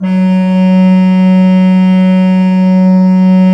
Index of /90_sSampleCDs/Propeller Island - Cathedral Organ/Partition L/HOLZGEDKT MR